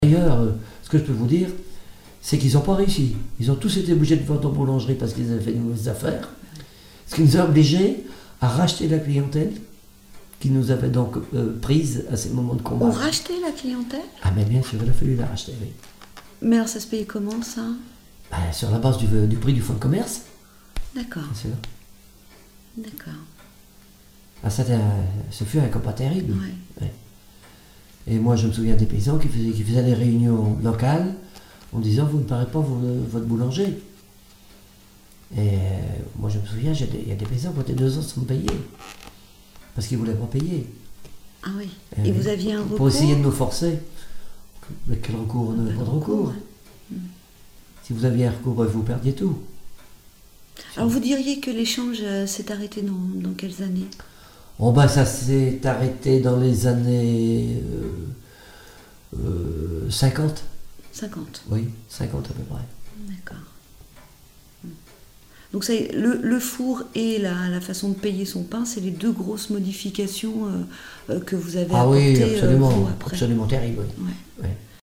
Témoignages sur la boulangerie artisanale
Catégorie Témoignage